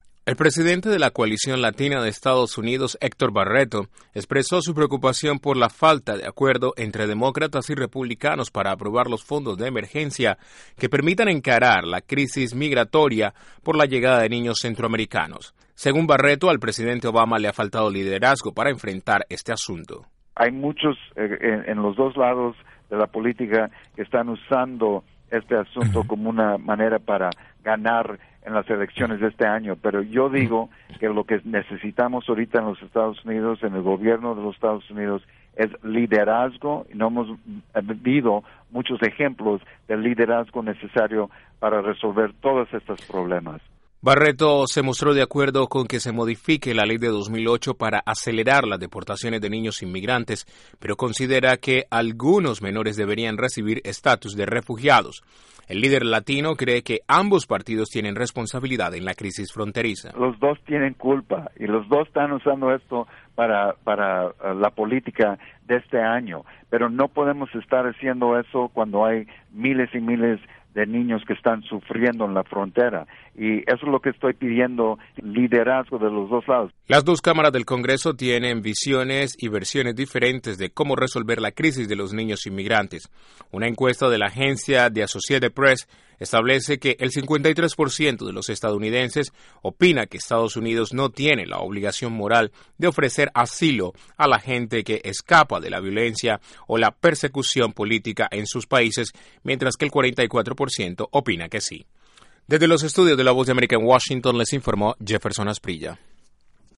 INTRO: La Coalición Latina de EE.UU reclamó más liderazgo del presidente Obama para enfrentar crisis migratoria generada por la masiva llegada de niños centroamericanos. Desde la Voz de América en Washington